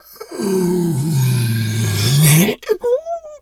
bear_pain_hurt_04.wav